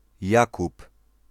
PronunciationPolish: [jakup]